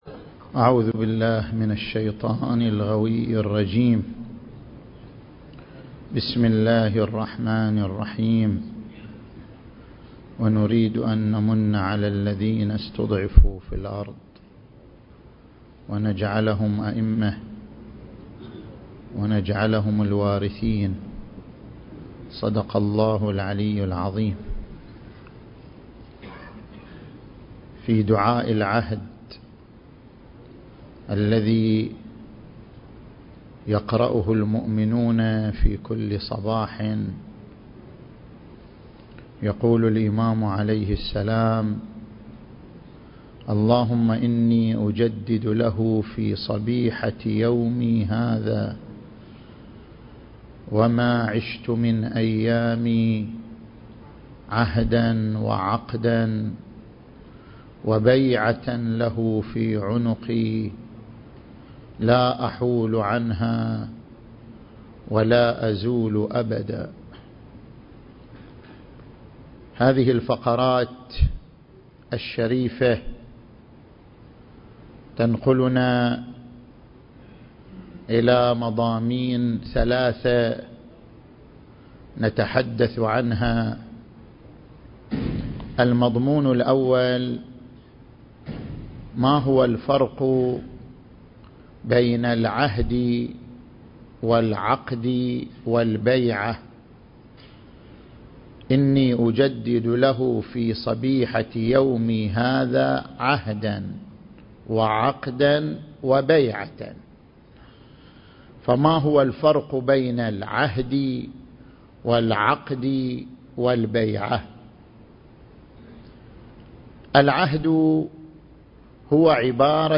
محاضرة الجمعة التاريخ: 1435 للهجرة